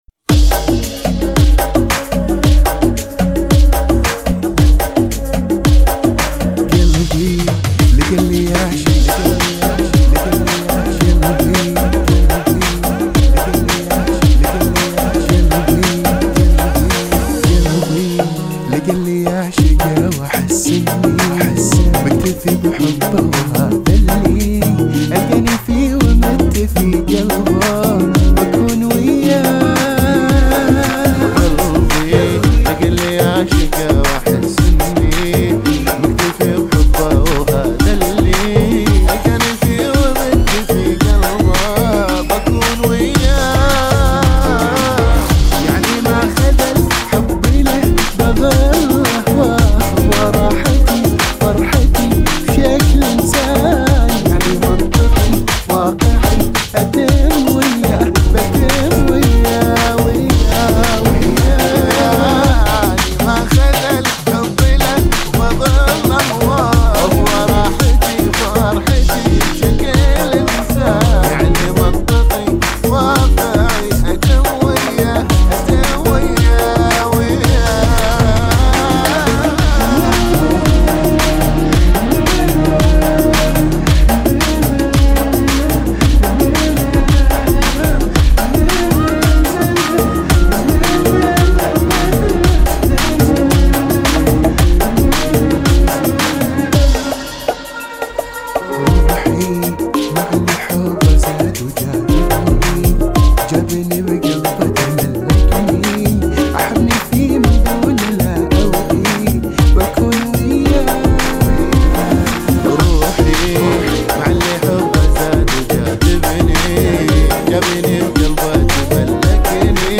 100 bpm